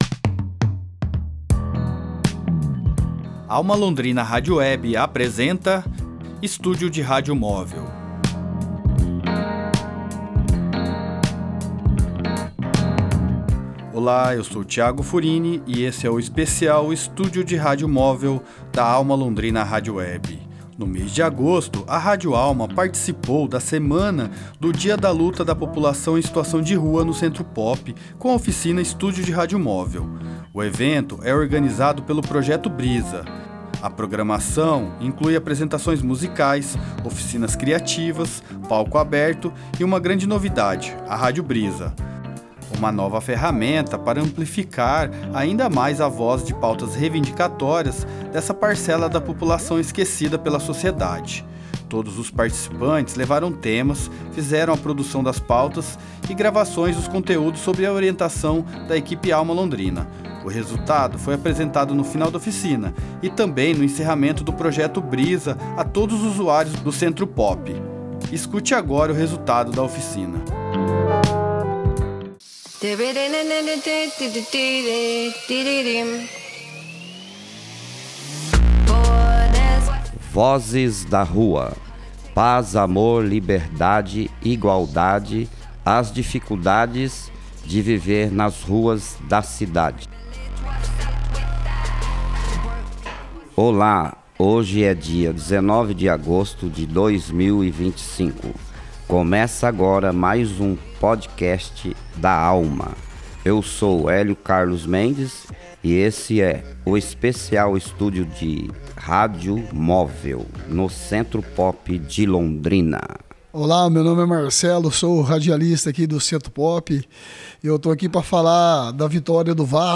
Ouça os podcasts produzidos durante a semana dedicada à Luta da População em Situação de Rua, promovida em agosto no Centro POP
No mês de agosto, a Alma Londrina Rádio Web participou da semana dedicada à Luta da População em Situação de Rua, promovida no Centro POP, com a oficina “Estúdio de Rádio Móvel”, a convite do Projeto Brisa.